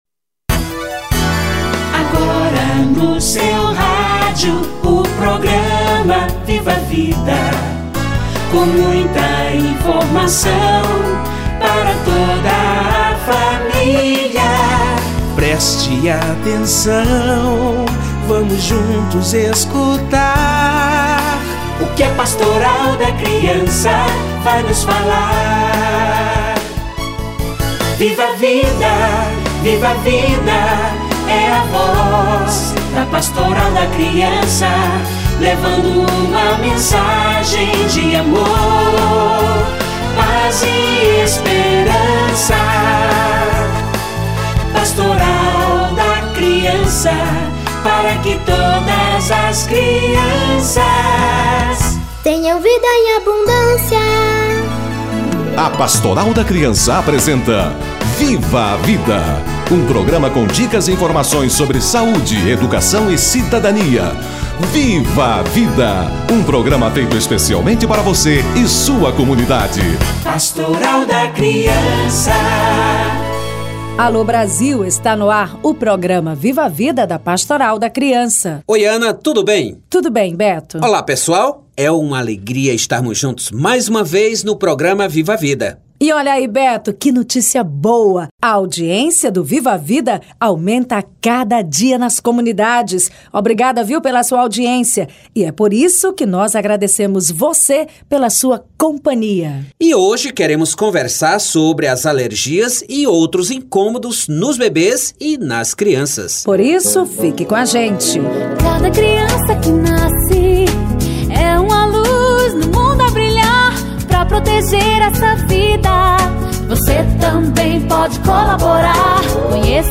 Alergias e outros incômodos da criança - Entrevista